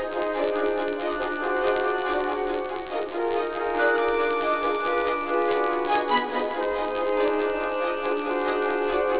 piccolo trills in the refrain segment.